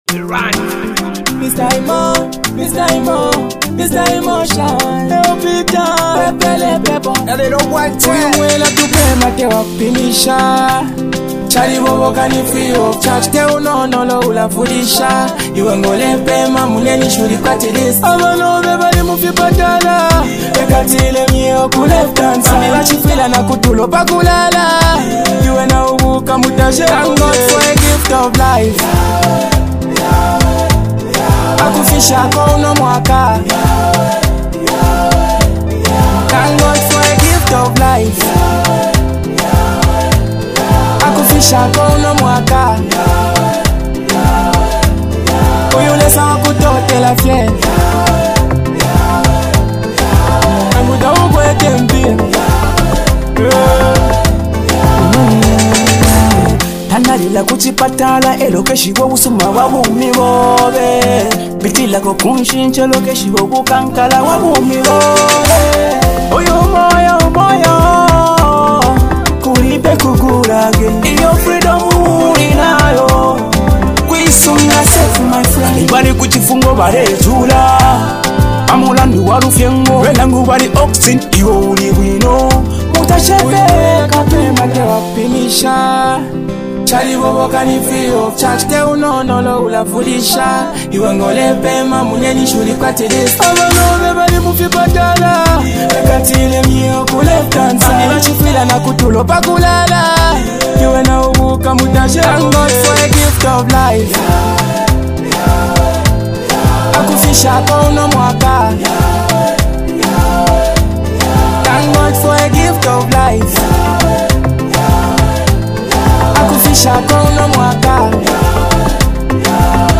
• Gospel